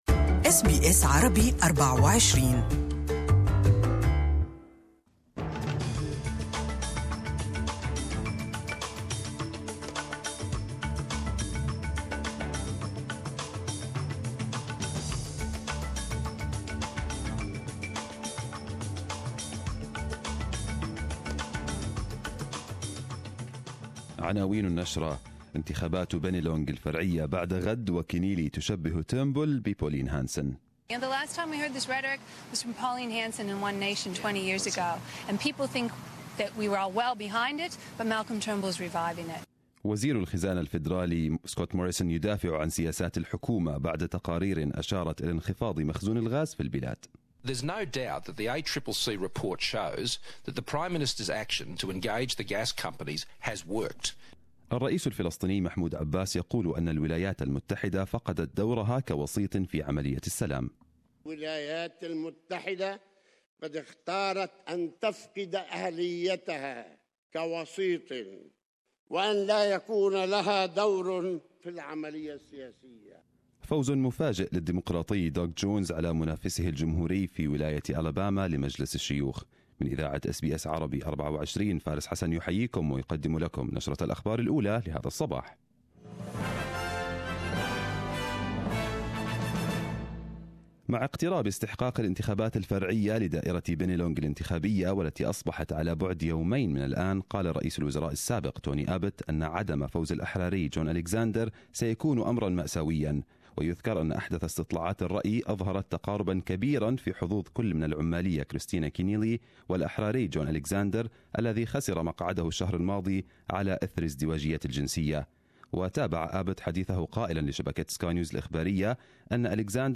Arabic News Bulletin 14/12/2017